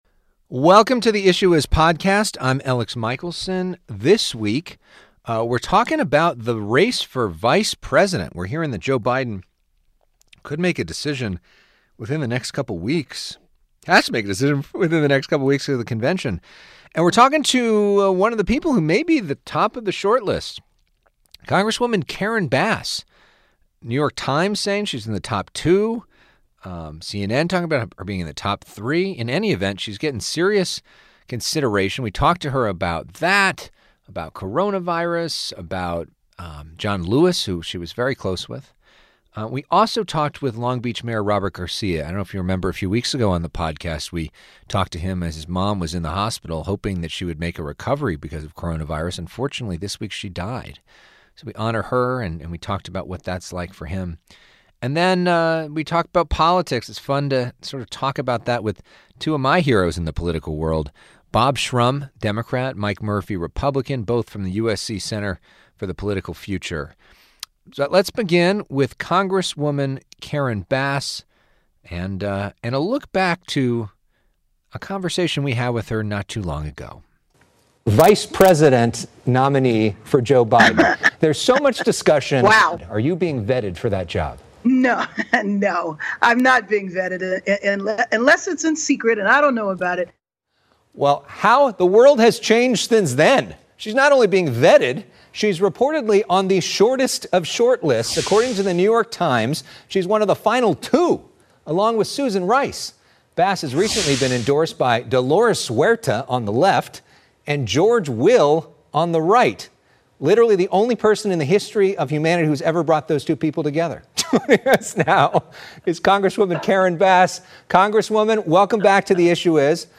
Discussing Vice President candidates and COVID-19 with Rep. Karen Bass, Mayor Garcia, Bob Shrum, and Mike Murphy